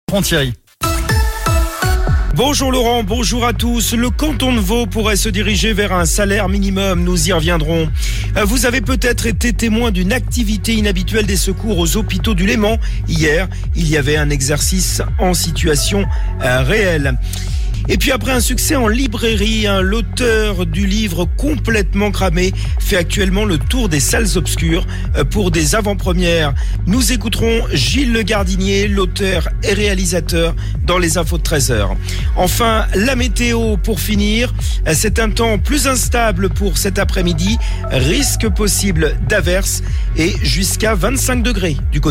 Flash Info
Votre flash info - votre journal d'information sur La Radio Plus